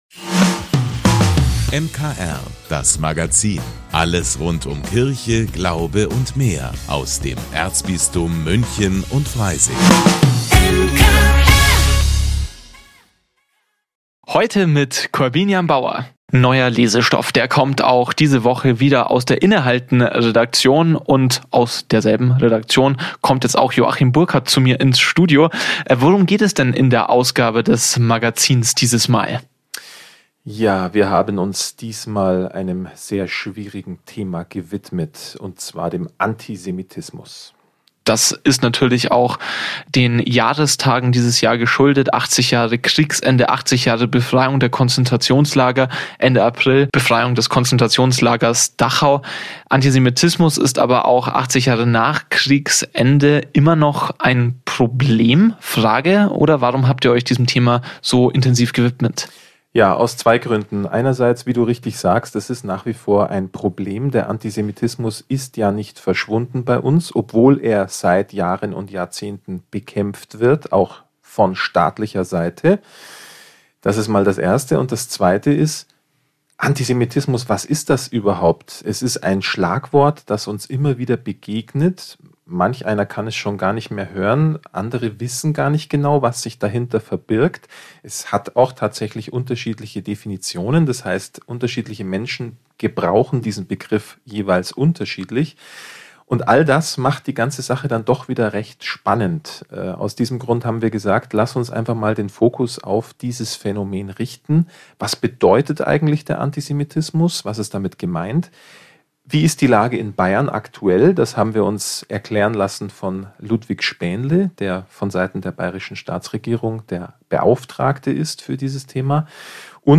In der neuen Ausgabe des innehalten-Magazins geht es unter anderem um Antisemitismus. Umfrage: Wie feiern die Münchner Fasching?